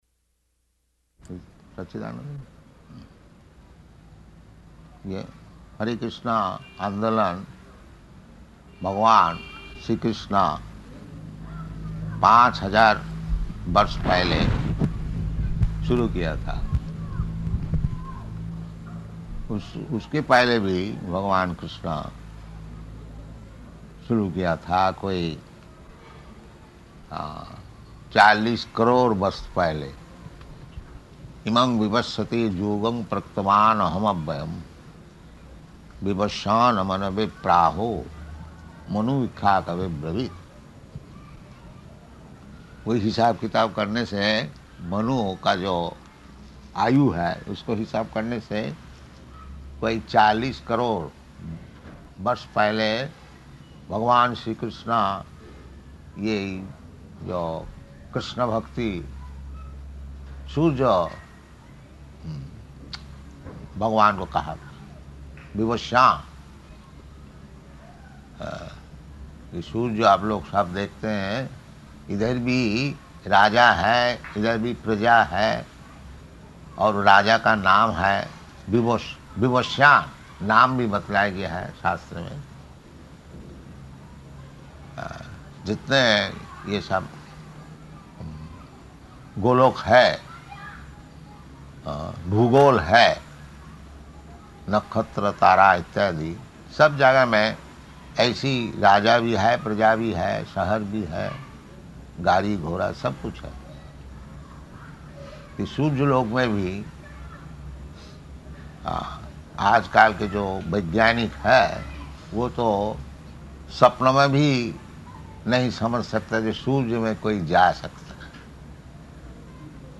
Type: Lectures and Addresses
Location: Bombay